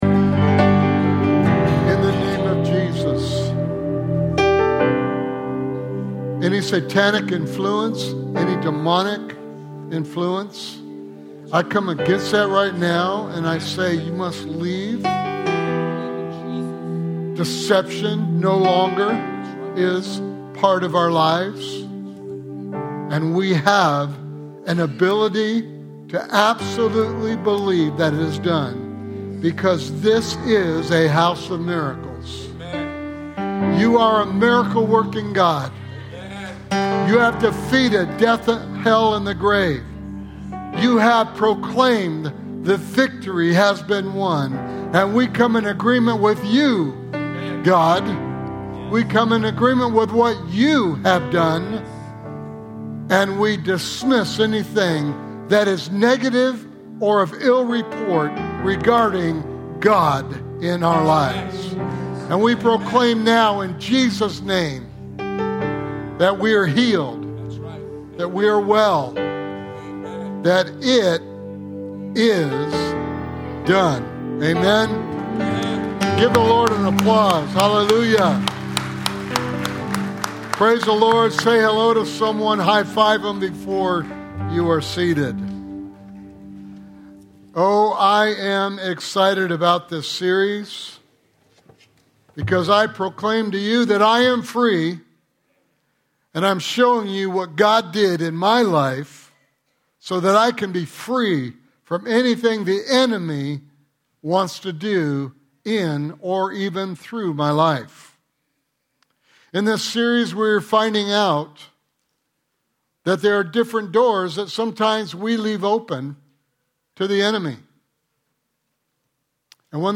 Sermon Series: I Am Free
Sunday morning sermon